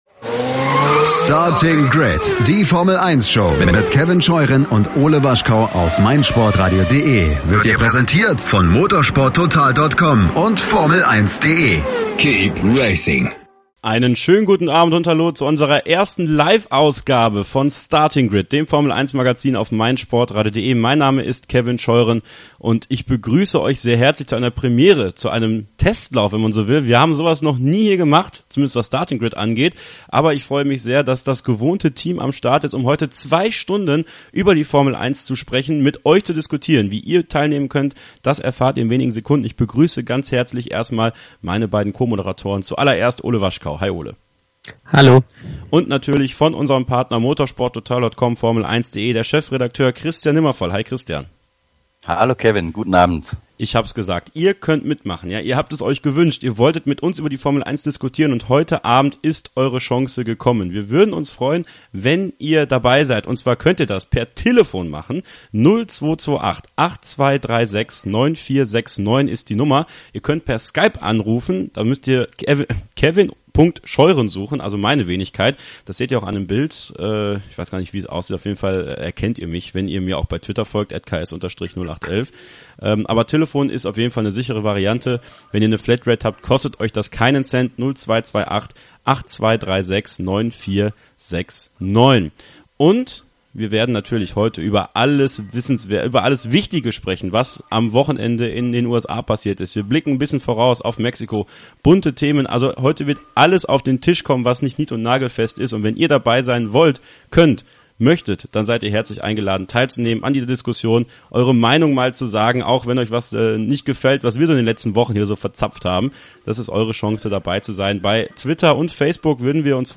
Hört hier nochmal die gesamte Live-Ausgabe im Re-Live und lasst uns euer Feedback da.